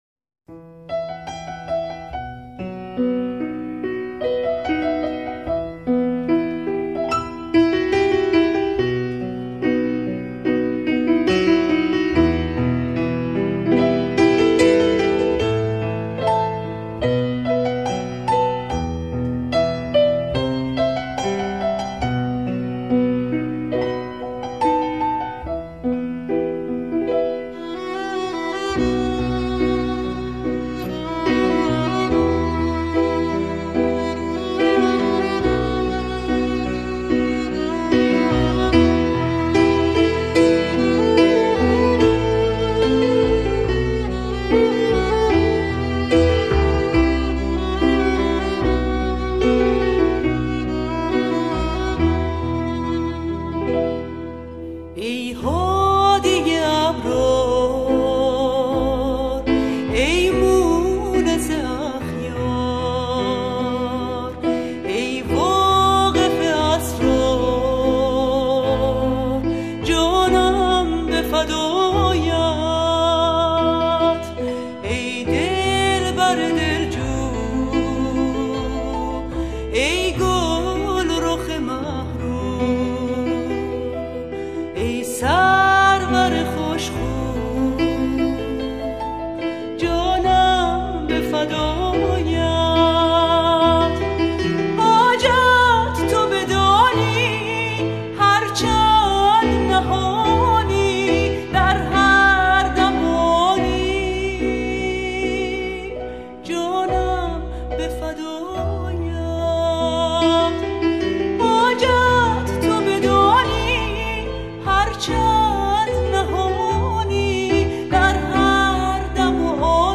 سرود - شماره 9 | تعالیم و عقاید آئین بهائی